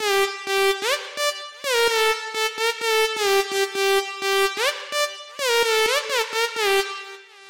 Tag: 128 bpm Dance Loops Synth Loops 1.26 MB wav Key : G